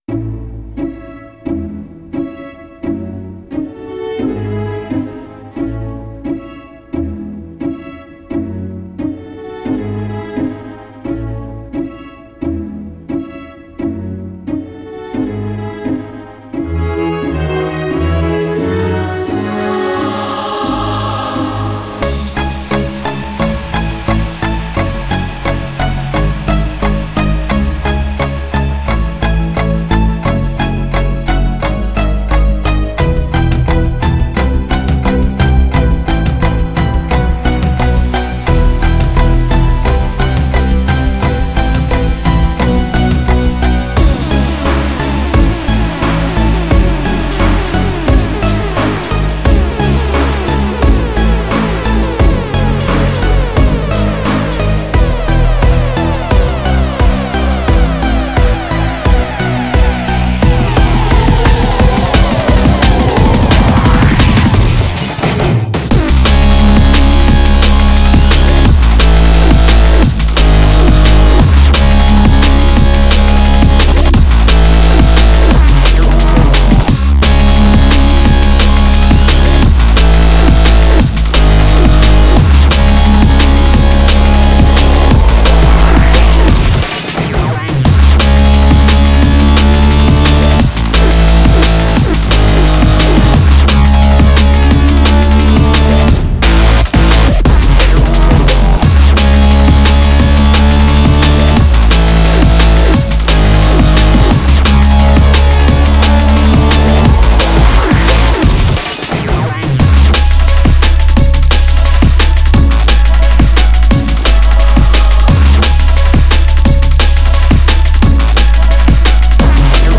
Style: Dubstep, Drum & Bass